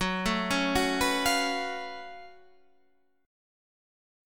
F#mM11 chord